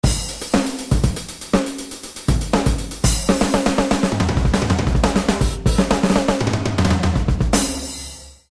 ソフトウェアのドラム音源BFD2。
一つの音色に付き強弱によるバリエーションが９６段階もあるので、ランダム強弱設定しておくと、ベロシティ完全一定の超ベタ打ちでも
♪わりとイケる